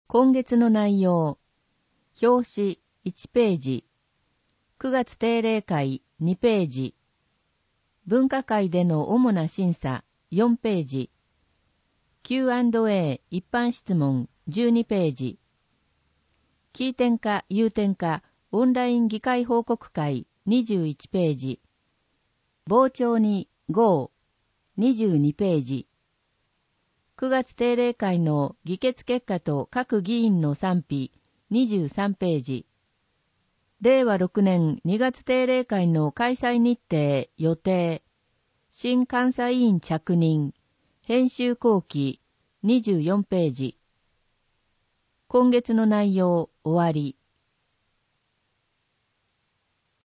声の市議会だより
なお、この音声は「音訳グループまつさか＜外部リンク＞」の皆さんの協力で作成しています。